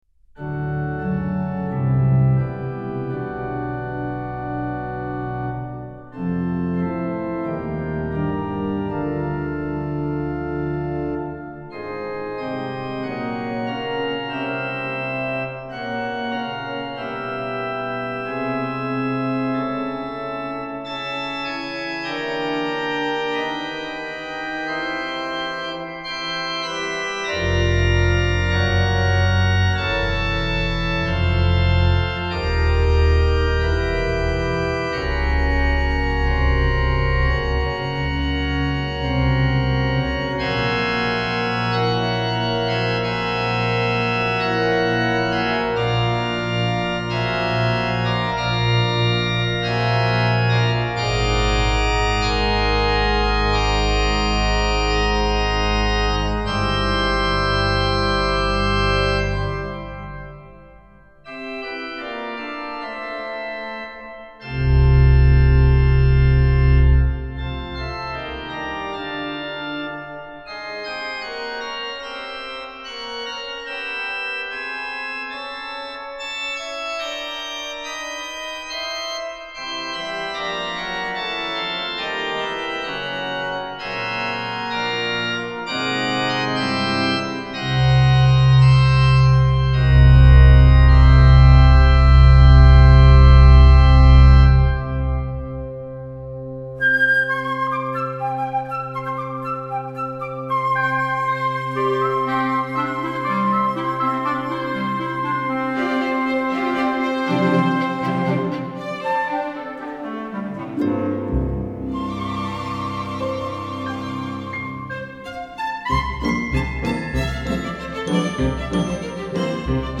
Symphony